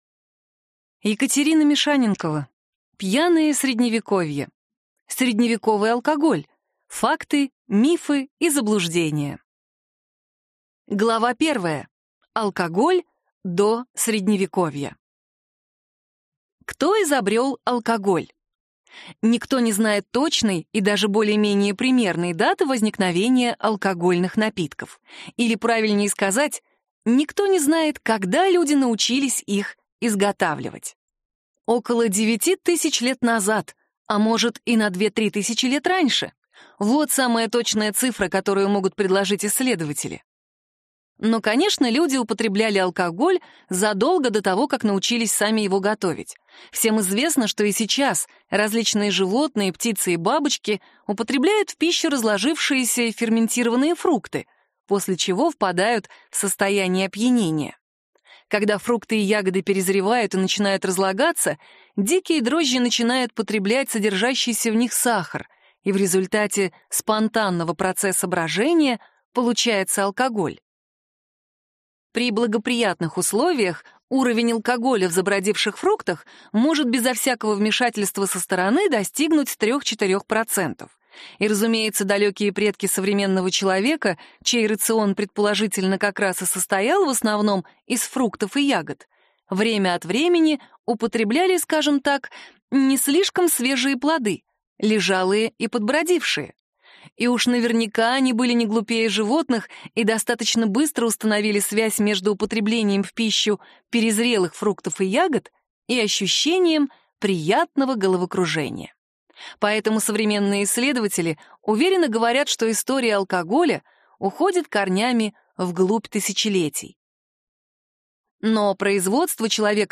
Аудиокнига Пьяное Средневековье. Средневековый алкоголь: факты, мифы и заблуждения | Библиотека аудиокниг